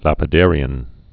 (lăpĭ-dârē-ən)